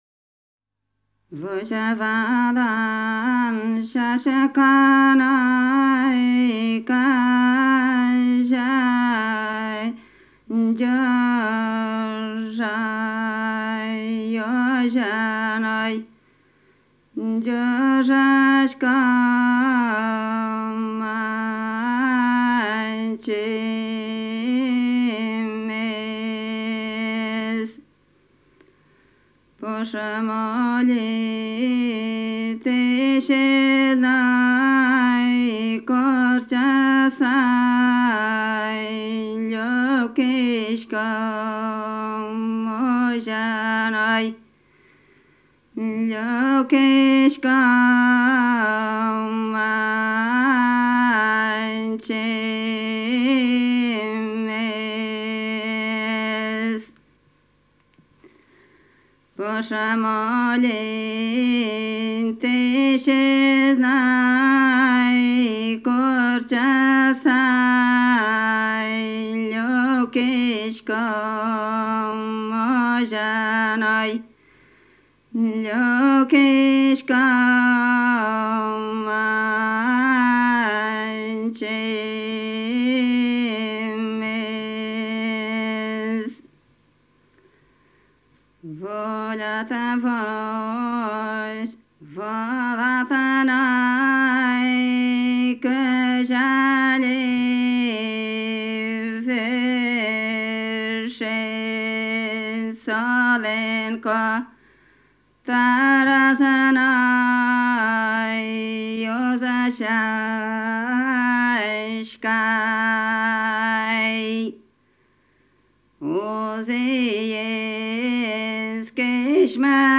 Луговой_напев.mp3